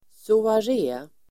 soaré substantiv, soirée, evening [entertainment]Uttal: [soar'e:] Böjningar: soarén, soaréerDefinition: aftonunderhållning